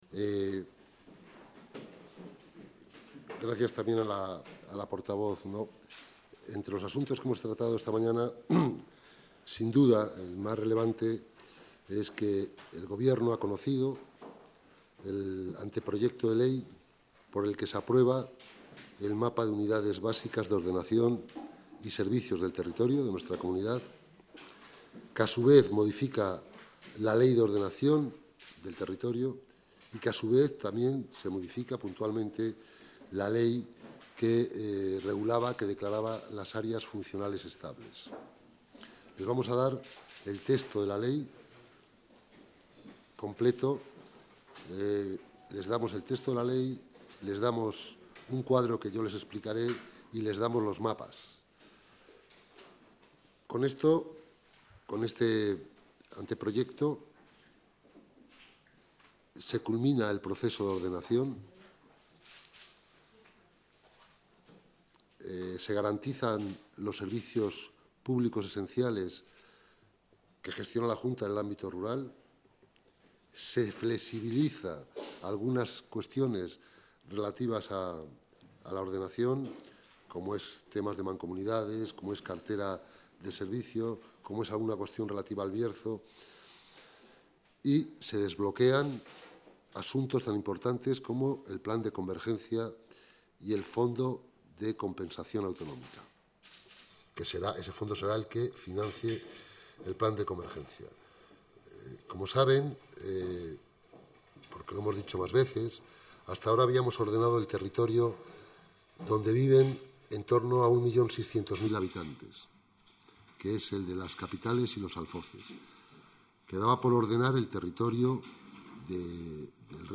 Audio de la rueda de prensa posterior al Consejo de Gobierno.